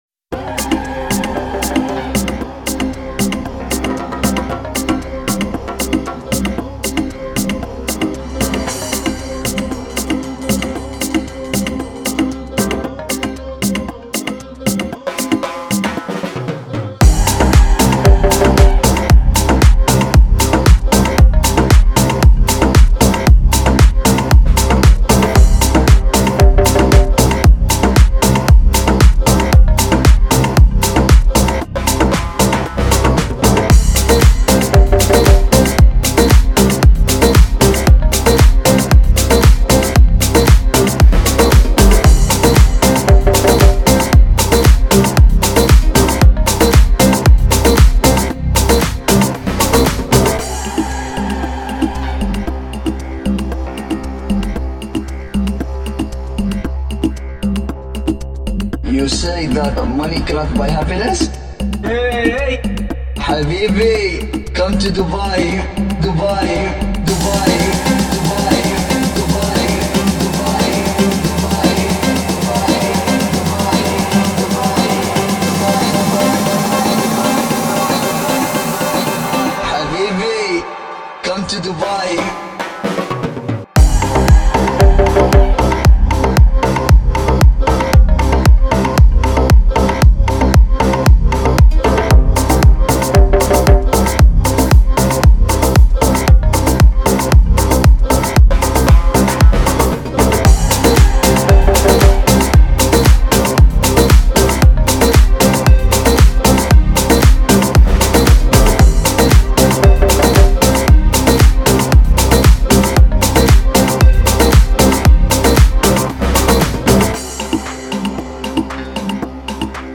آهنگ خارجی بیس دار مخصوص ماشین بسیار زیبا